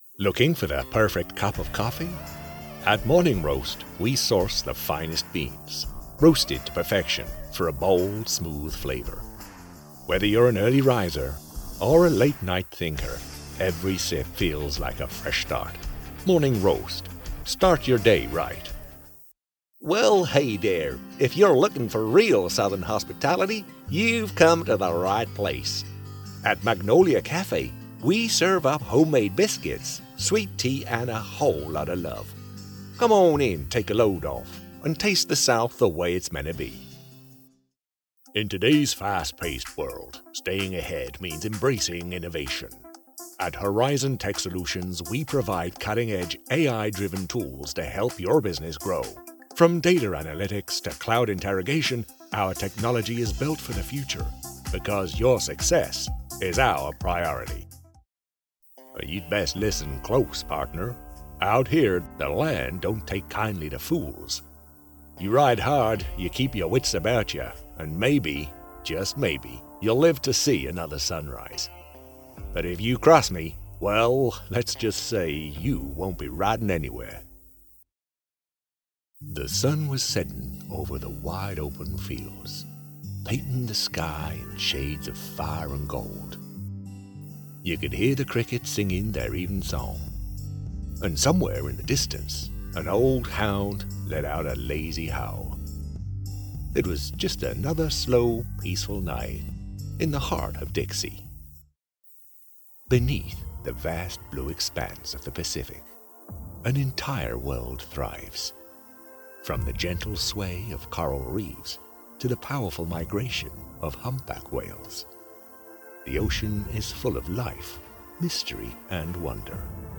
Male
Adult (30-50), Older Sound (50+)
U.S Accent Demo Reel
All our voice actors have professional broadcast quality recording studios.